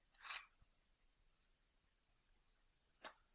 道具 " 拿起放下03
描述：集装箱被捡起并放下。